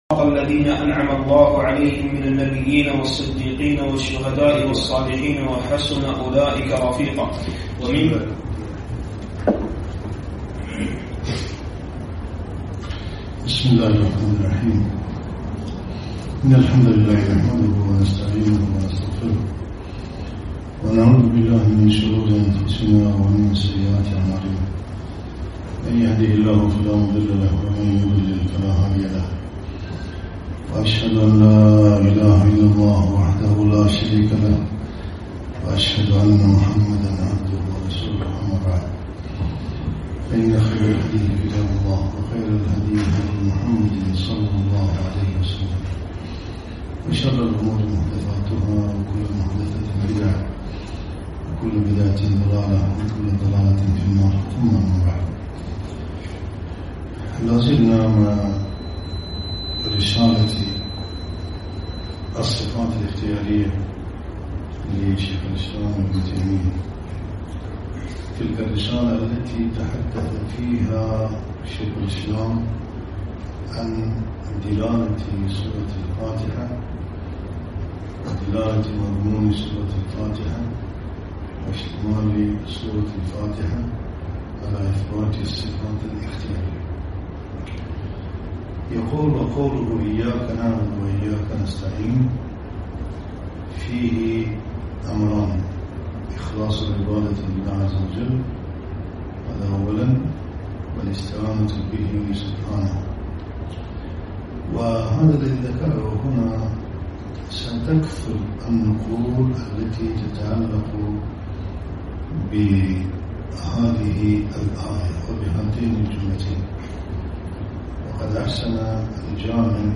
موقع دروس الكويت